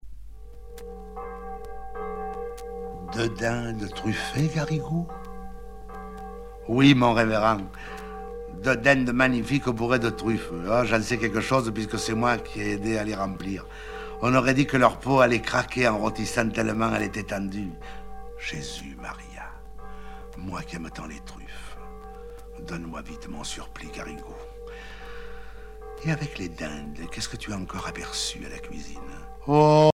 Genre récit